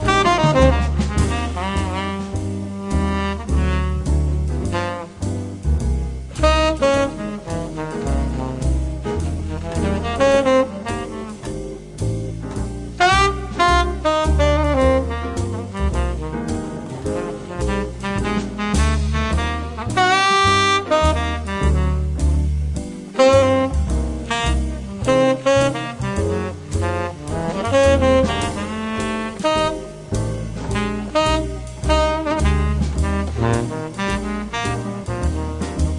The Best In British Jazz
Recorded The Studio, Surrey March 16th / 17th 2005